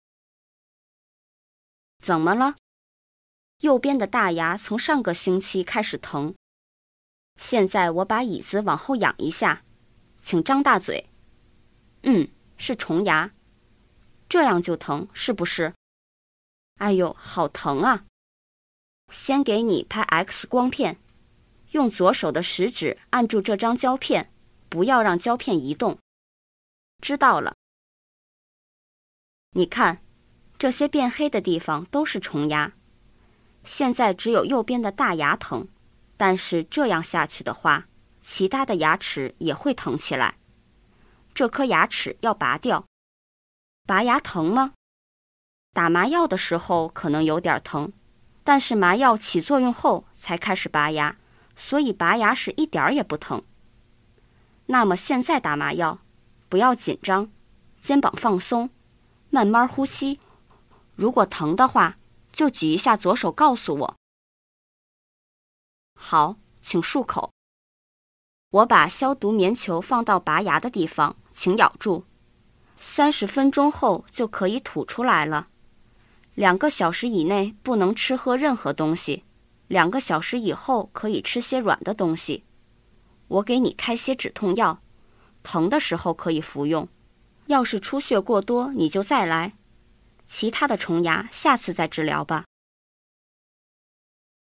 今回は観光とは少し離れて，歯科での会話を見てみましょう。